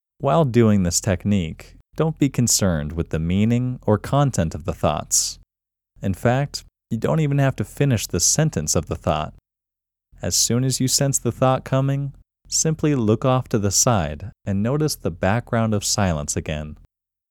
QUIETNESS Male English 10
The-Quietness-Technique-Male-English-10.mp3